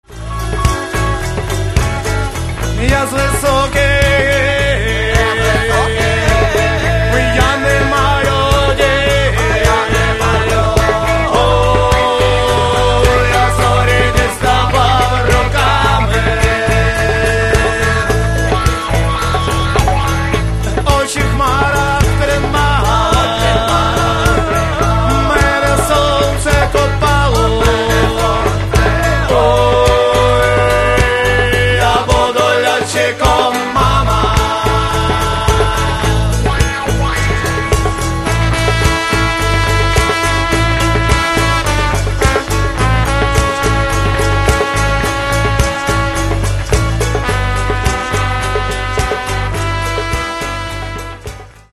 Еще немного громче и – до свидания!